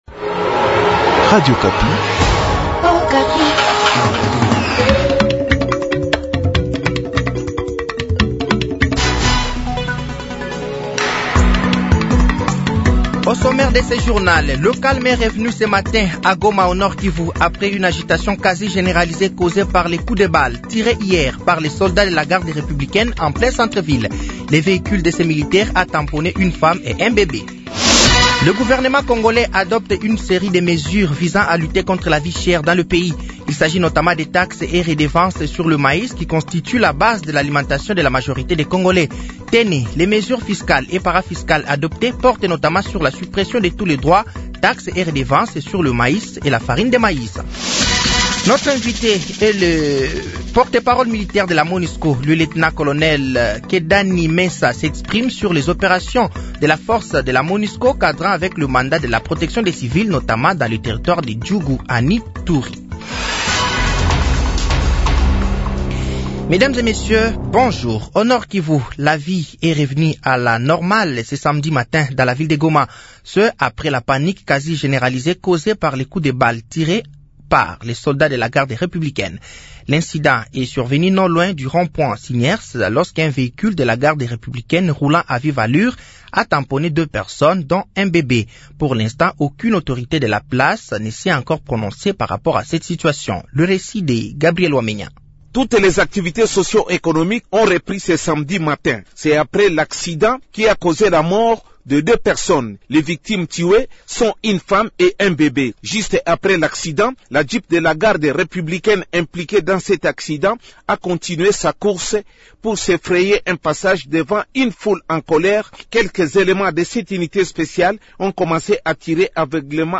Journal français de 12h de ce samedi 10 août 2024